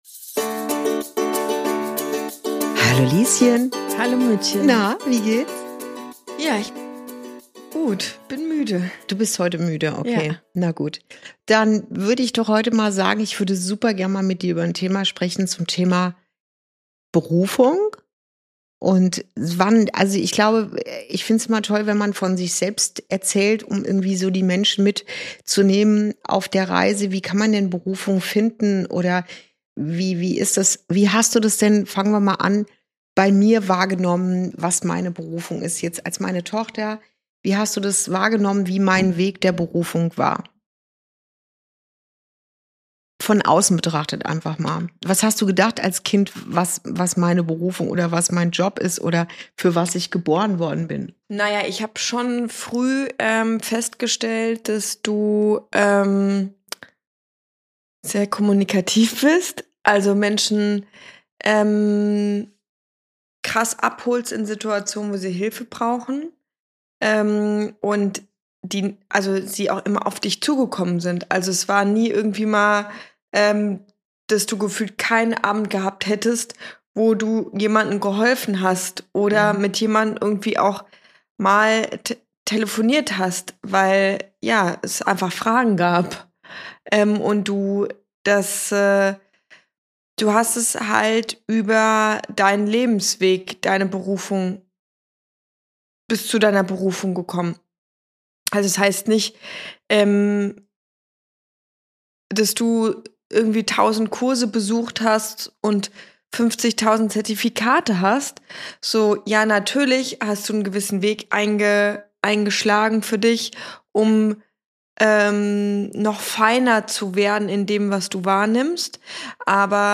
Ein Gespräch zwischen Mutter und Tochter